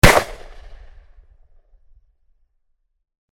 Desert-Eagle-Close.ogg